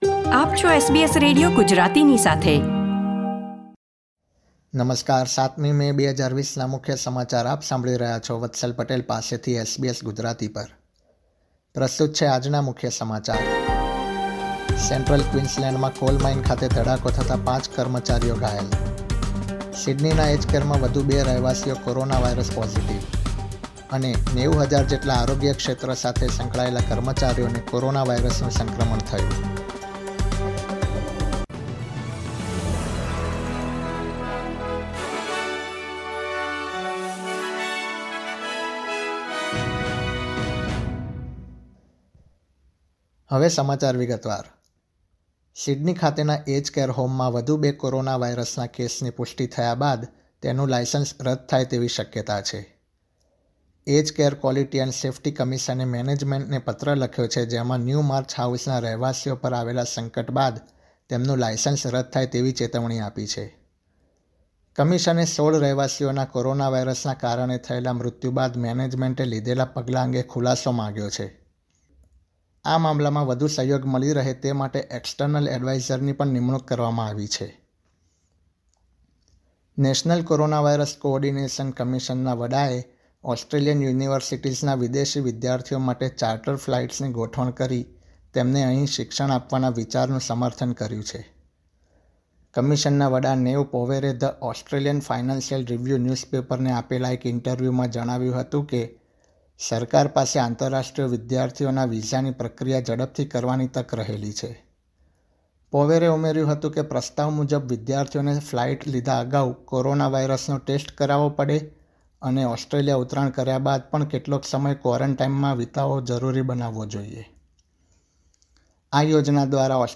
SBS Gujarati News Bulletin 7 May 2020